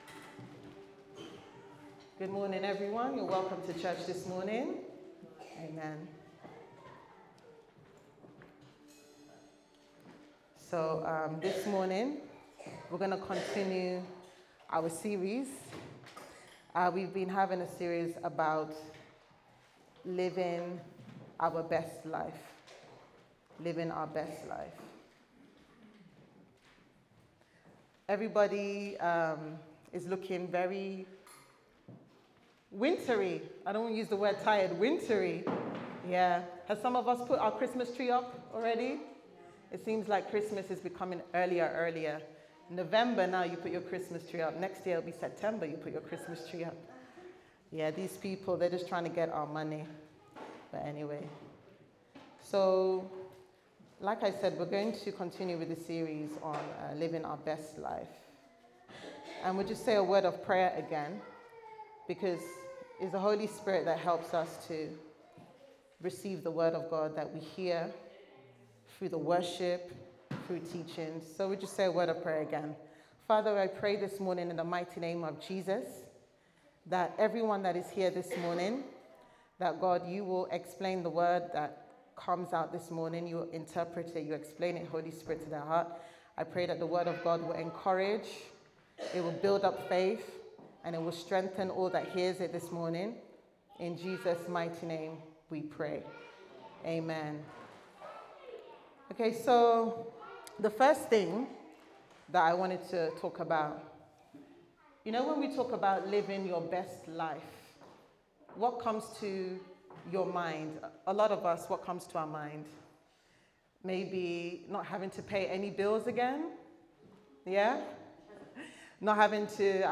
Sunday Service Sermon « Colosians 1v19-23